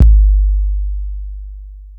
808-Kicks32.wav